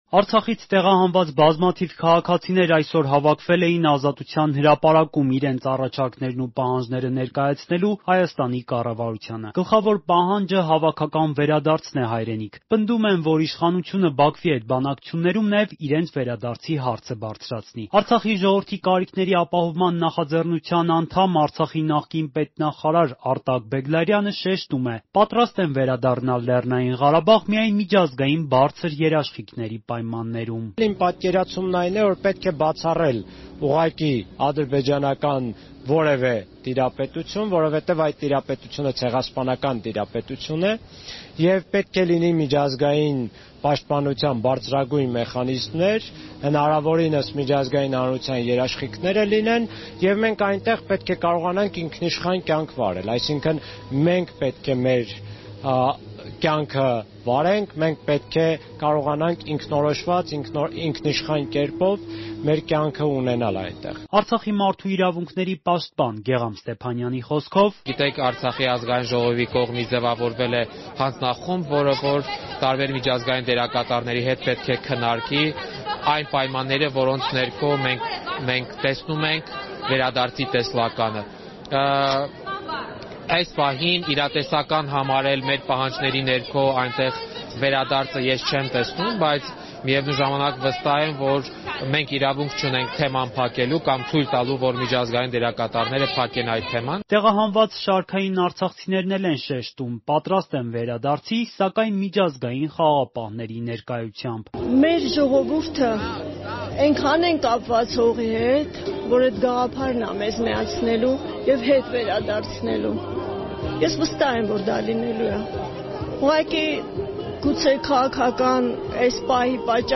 Ռեպորտաժներ
Հանրահավաք՝ Ազատության հրապարակում. արցախցիների պահանջը հավաքական վերադարձն է հայրենիք